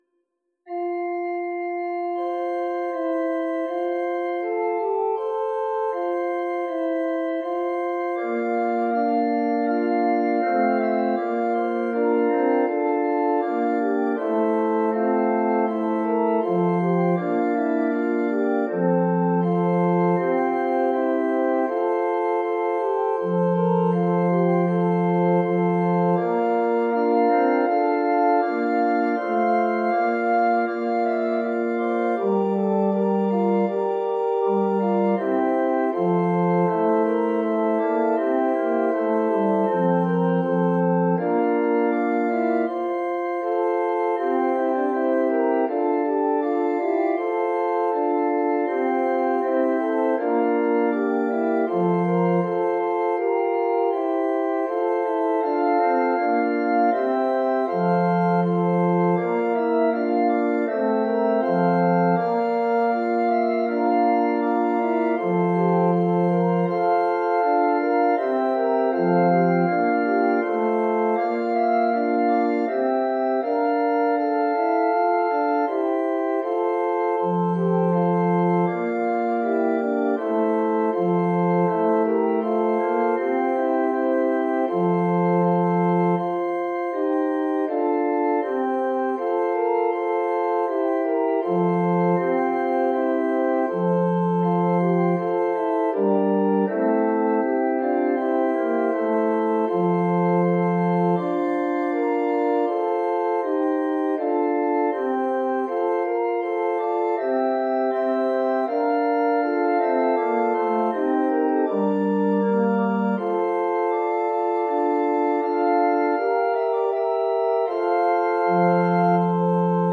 Transposition: at original pitch.
Organ reduction.